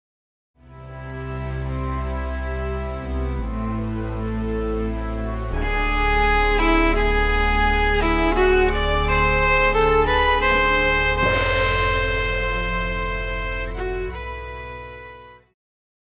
Pop
Viola
Band
Instrumental
World Music,Electronic Music
Only backing